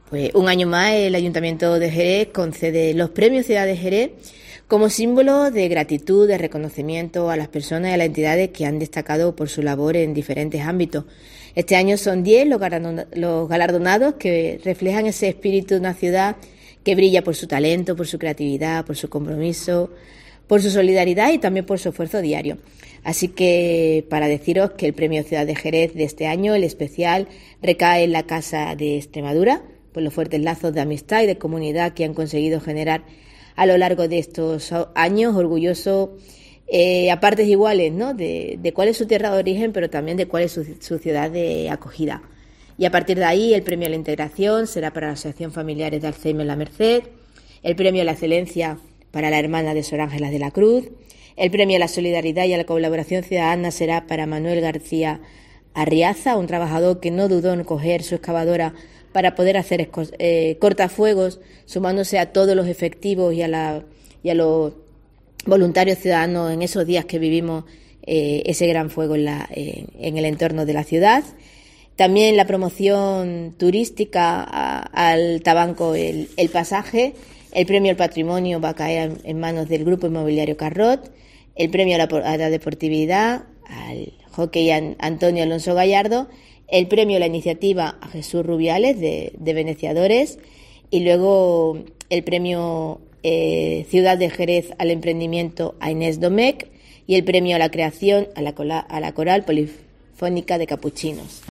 Escucha aquí a Mamen Sánchez, alcaldesa de Jerez, en el relato de los diez entidades y personas galardonados con los Premios Ciudad de Jerez 2022